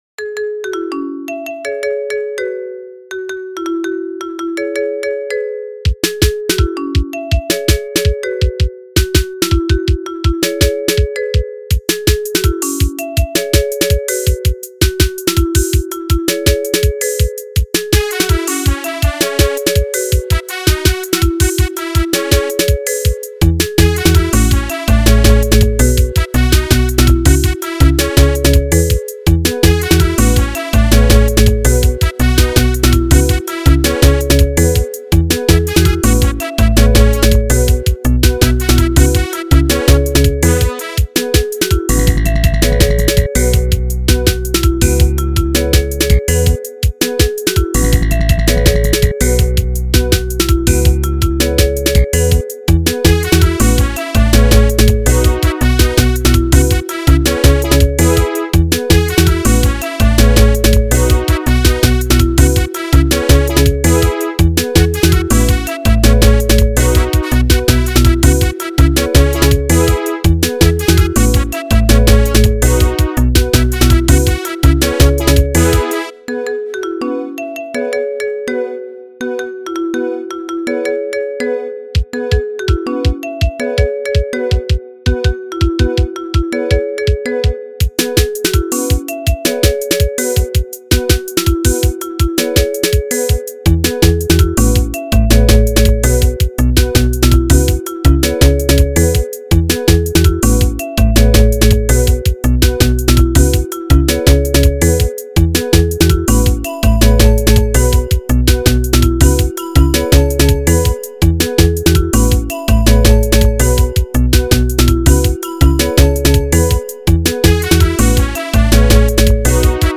03:11 Genre : Xitsonga Size